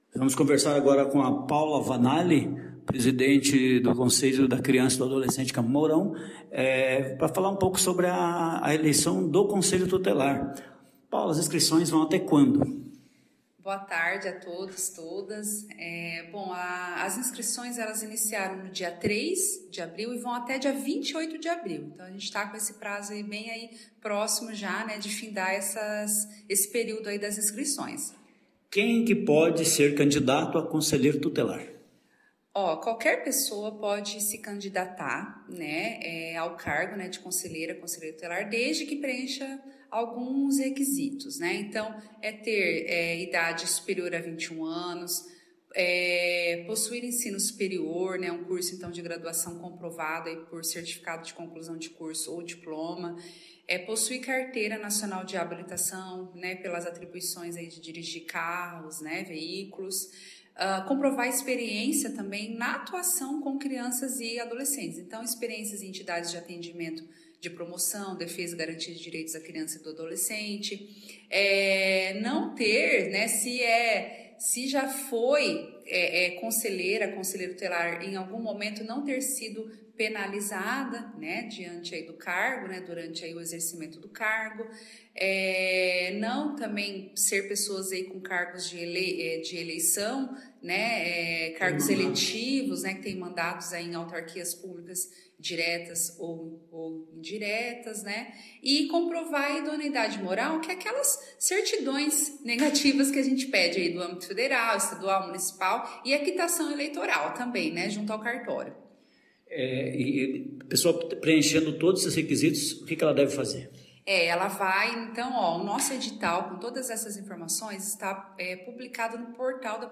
Em entrevista concedida à Assessoria de Imprensa da prefeitura mourãoense